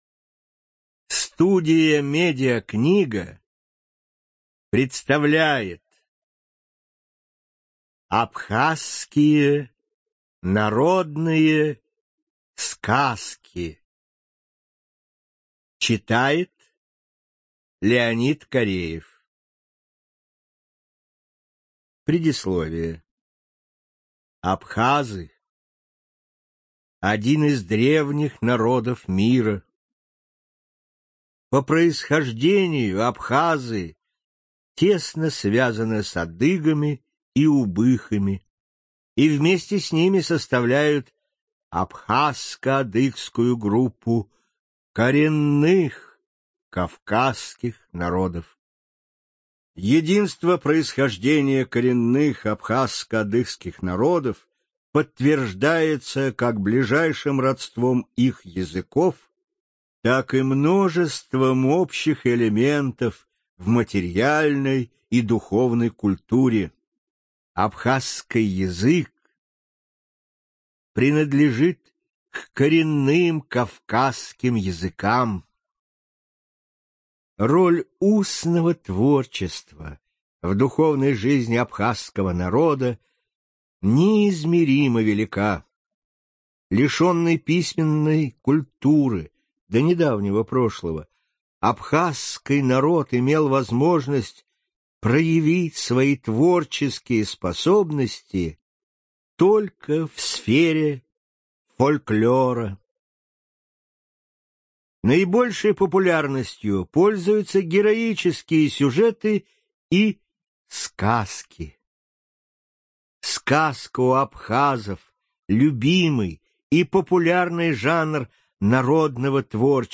Студия звукозаписиМедиакнига